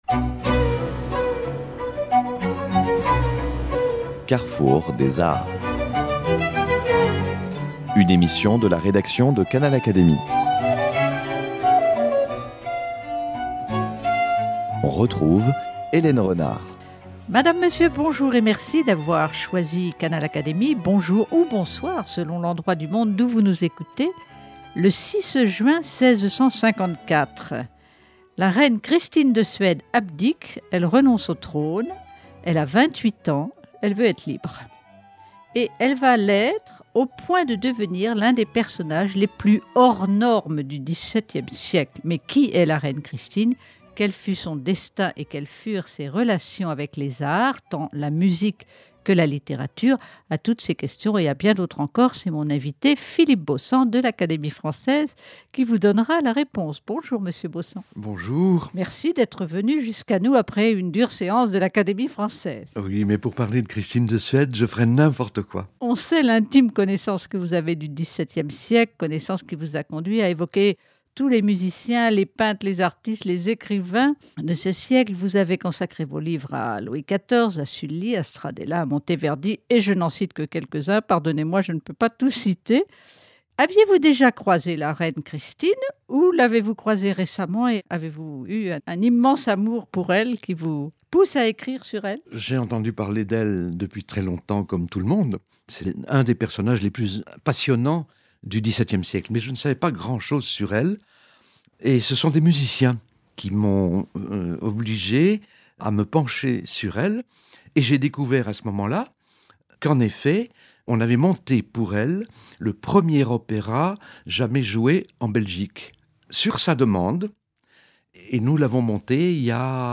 Philippe Beaussant, de l’Académie française, s’est pris de passion pour la reine Christine de Suède (1626-1689) dont il décrit ici la personnalité hors du commun, les relations qu’elle entretint avec les Cours européennes et surtout le rôle qu’elle a joué dans les créations musicales du Grand Siècle. Partagez avec notre invité un grand moment de musique !